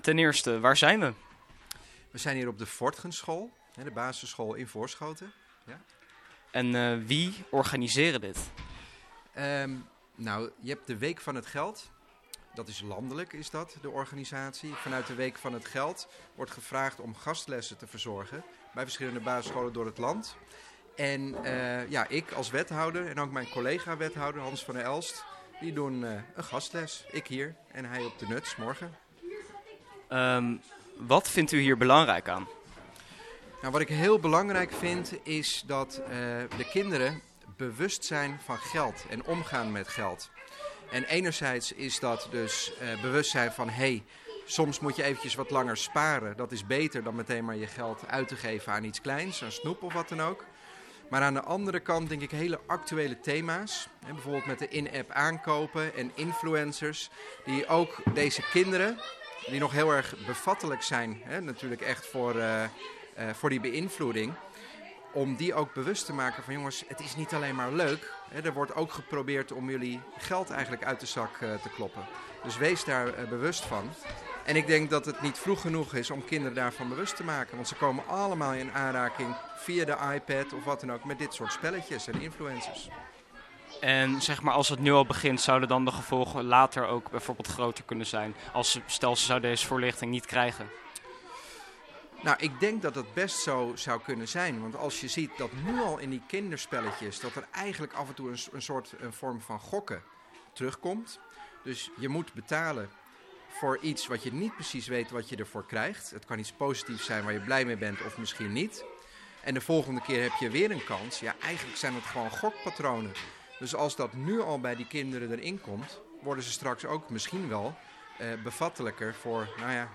Paul de Bruijn (wethouder gemeente Voorschoten)
interview-paul-de-bruijn.wav